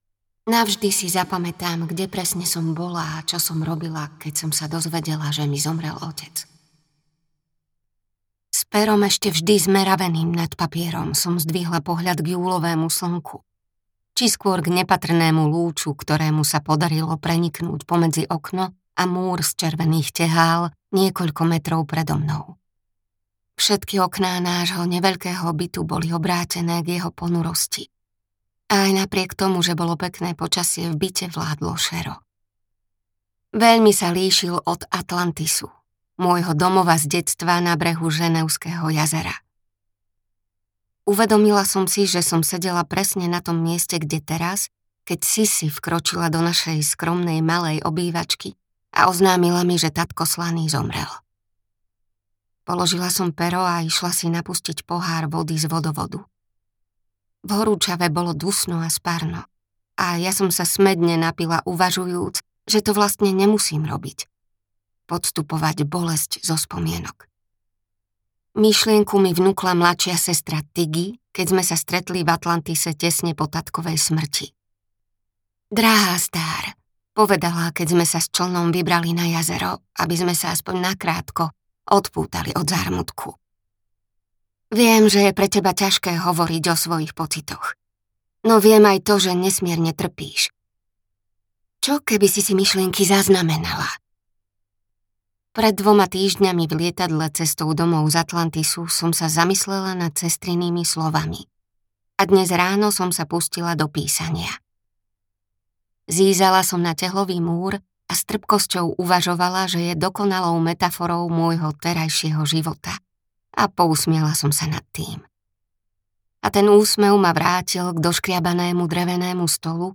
Tieňová sestra audiokniha
Ukázka z knihy